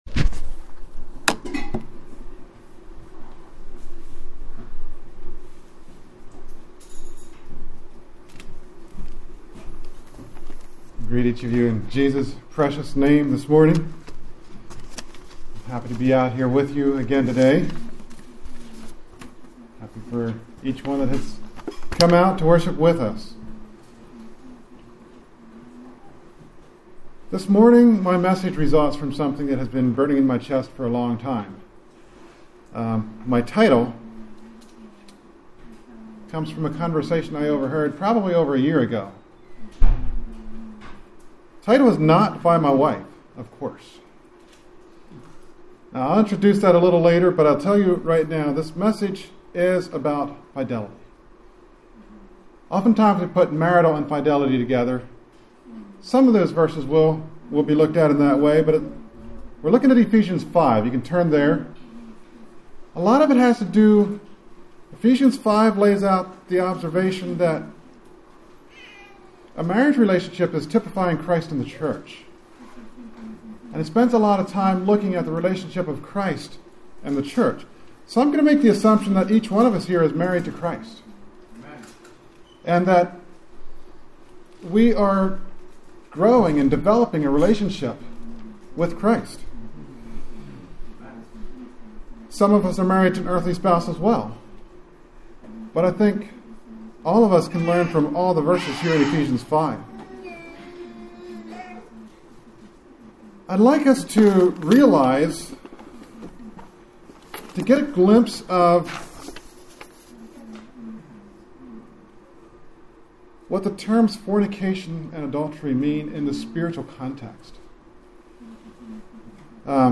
A sermon based on Ephesians 5 on fidelity in marriage. We are also to be in love with God and not with the world.